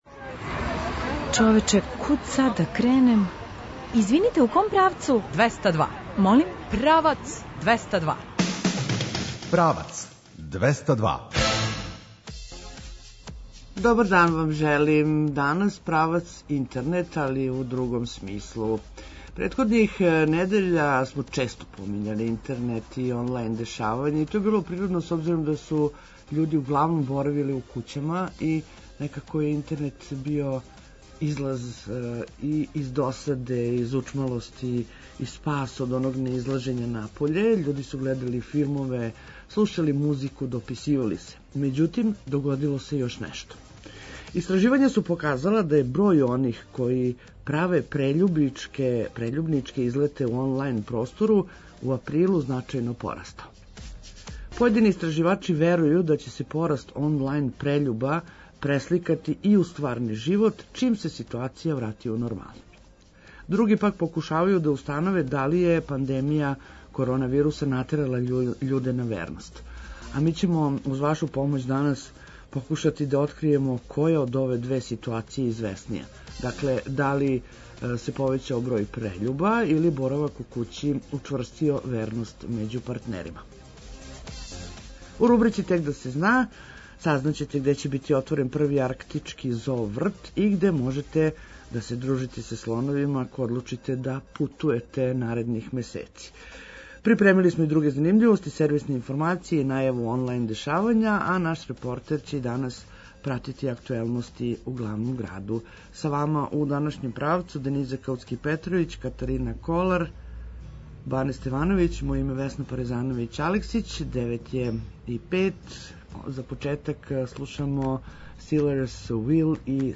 Наш репортер и данас прати актуелности у главном граду.